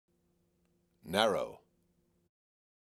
Word: Narrow (Male Voice)
The word "Narrow" spoken by a male voice
Recording Location: (In Studio)